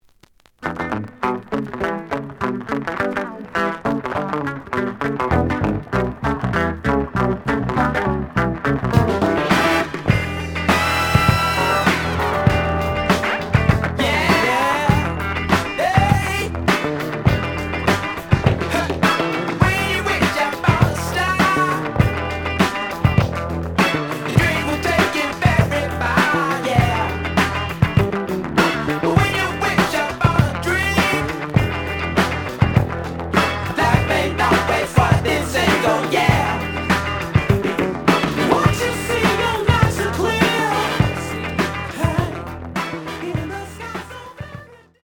The audio sample is recorded from the actual item.
●Genre: Funk, 70's Funk
A side plays good.